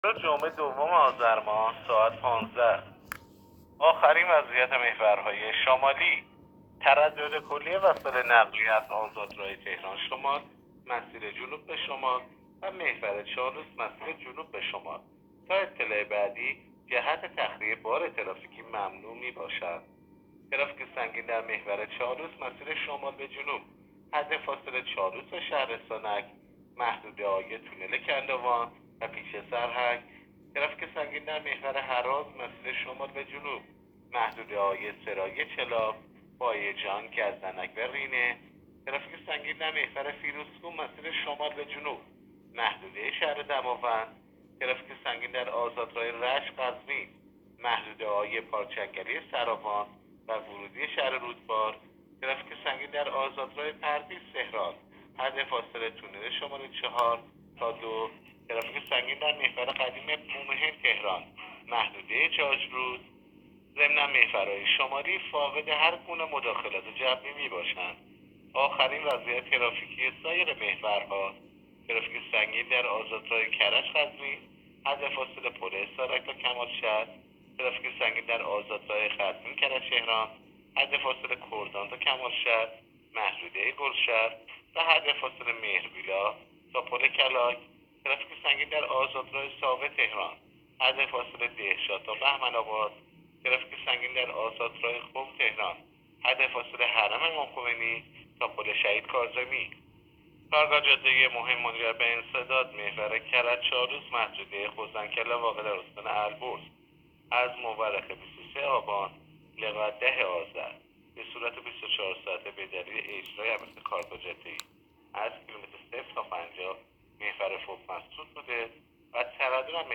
گزارش رادیو اینترنتی از آخرین وضعیت ترافیکی جاده‌ها تا ساعت ۱۵ دوم آذر؛